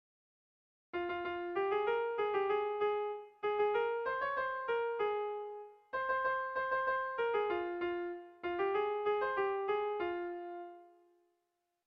Sehaskakoa
AB